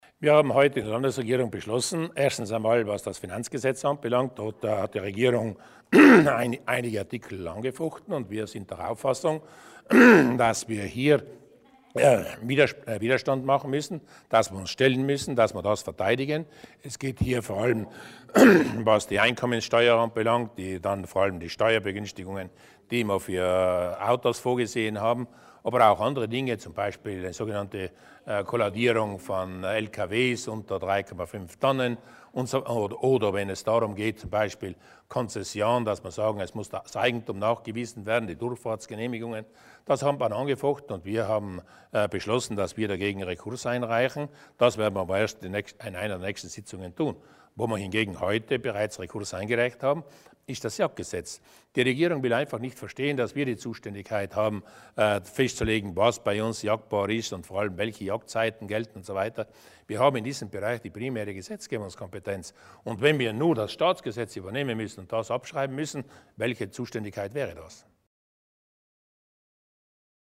Landeshauptmann Durnwalder zur Verteidigung des Jagdgesetzes vor dem Verfassungsgericht